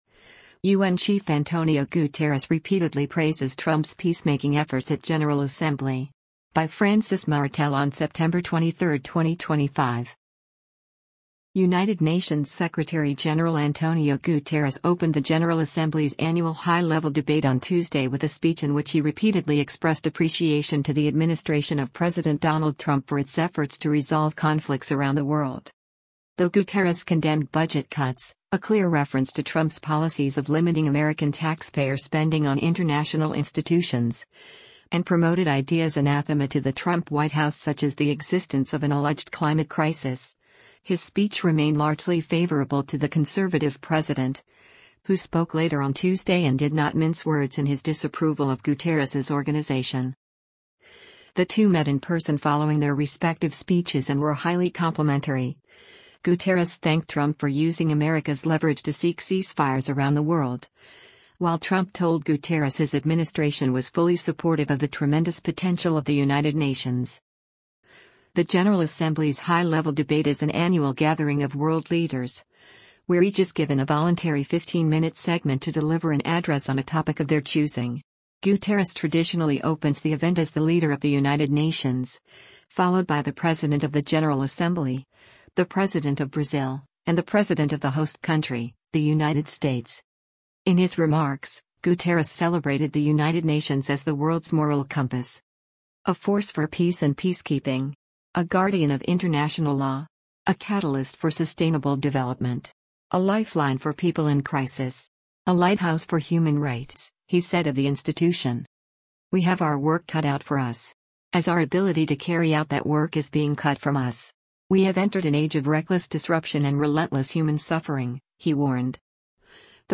United Nations Secretary General Antonio Guterres opened the General Assembly’s annual high-level debate on Tuesday with a speech in which he repeatedly expressed appreciation to the administration of President Donald Trump for its efforts to resolve conflicts around the world.